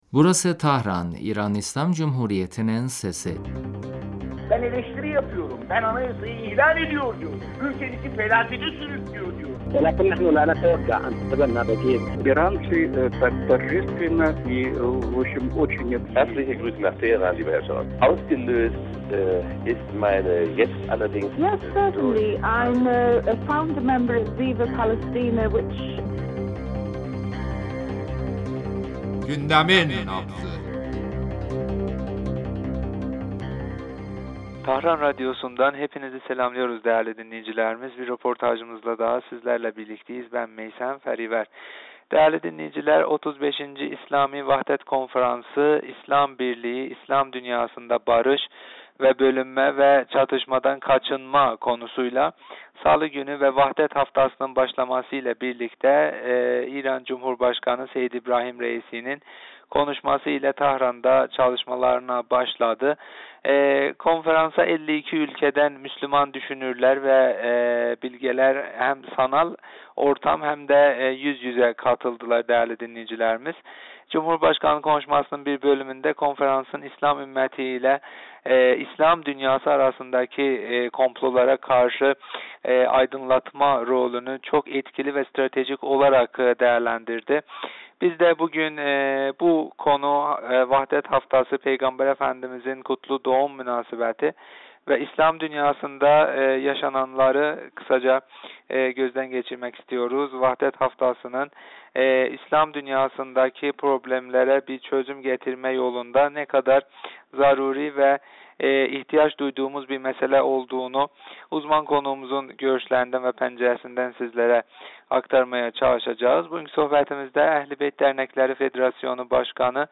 telefon görüşmesinde Vahdet Haftasının önemi ve zarureti hakkında konuştuk.